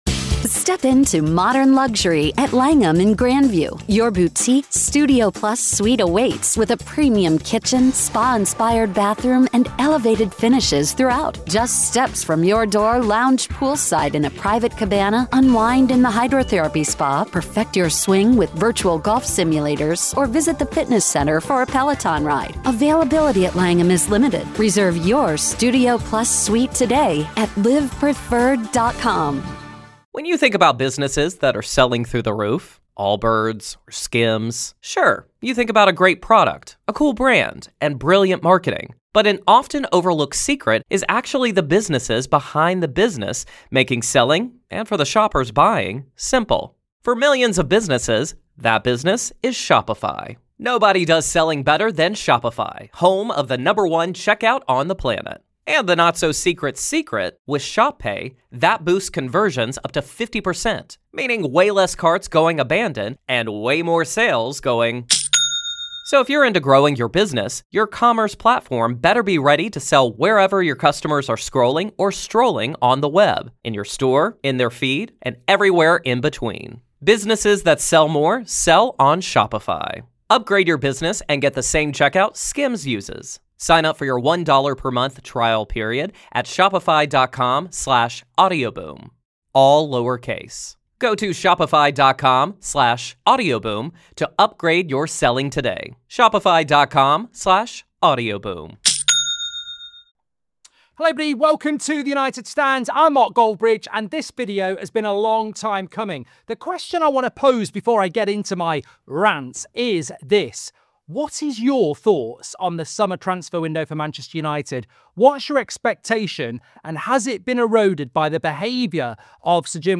24/03/2025 - Afternoon Rant!